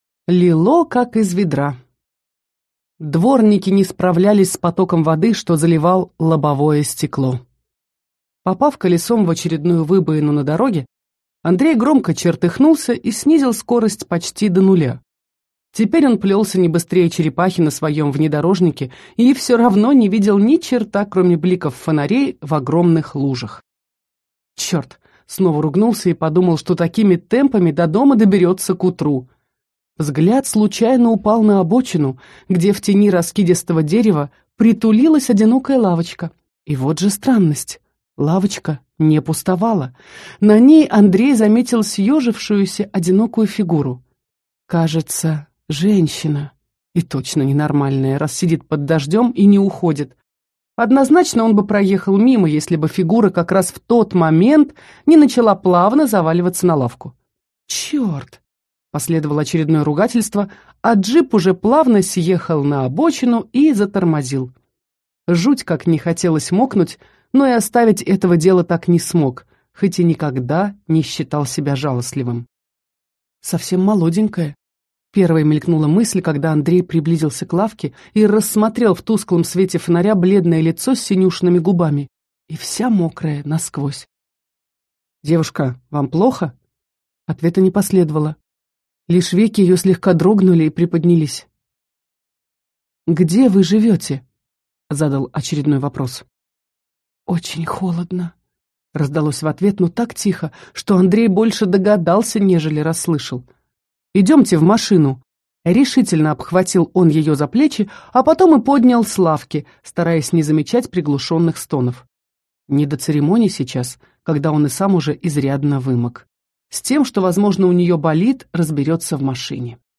Аудиокнига Любимая чужая | Библиотека аудиокниг